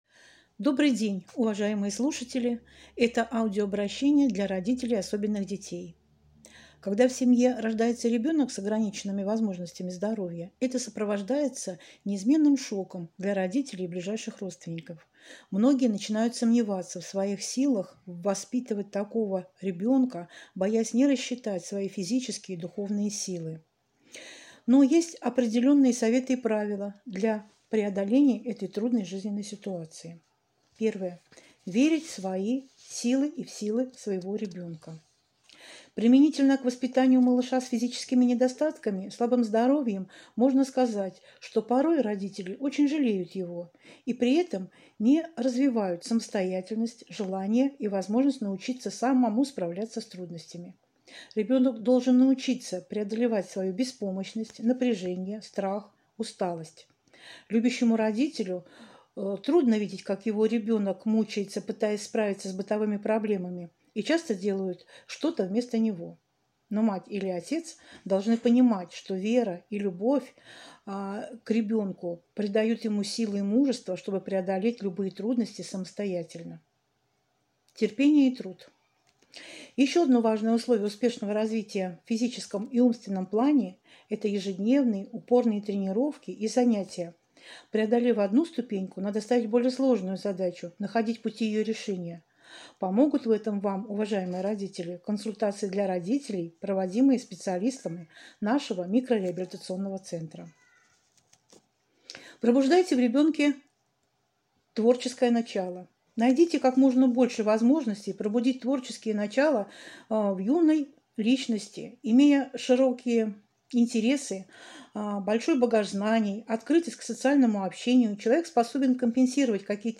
Аудиоконсультация для семей с особенными детьми.